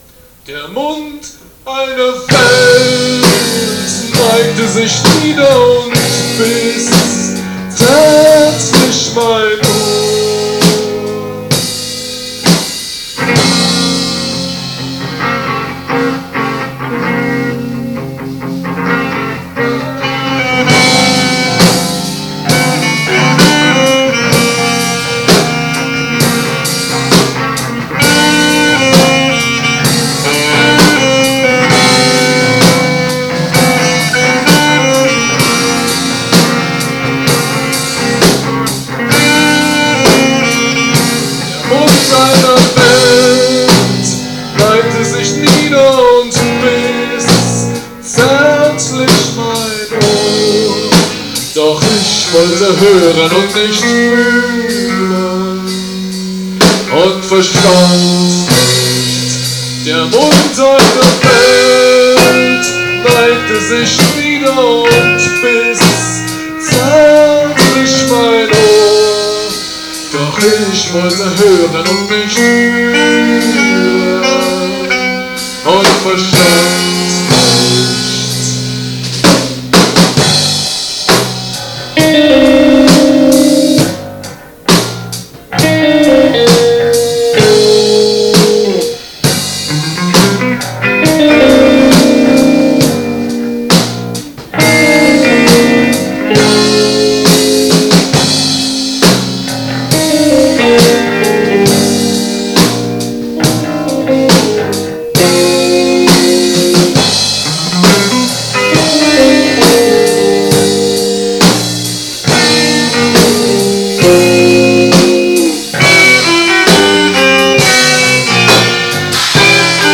Münchner Progressive Rock – Band
Schlagzeug
Saxophon / Klarinette
Gitarre